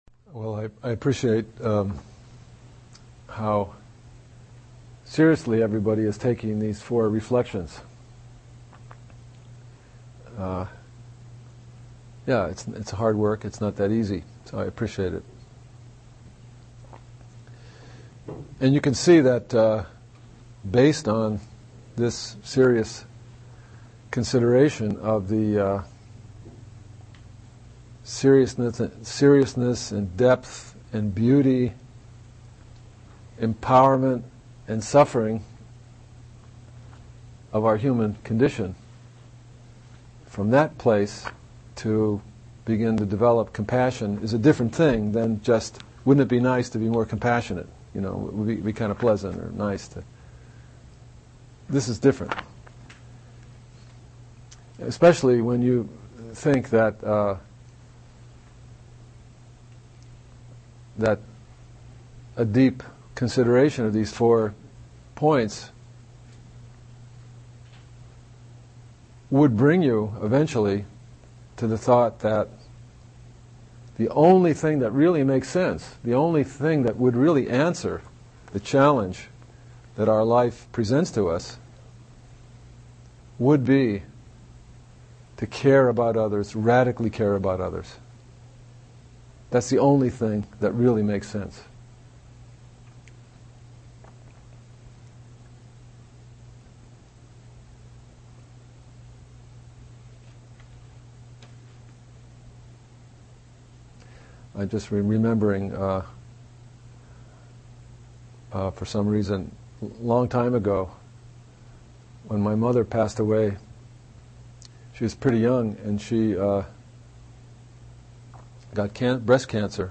Teacher: Zoketsu Norman Fischer Date: 2013-09-07 Venue: Seattle Insight Meditation Center Series [display-posts] TalkID=160 SeriesID=56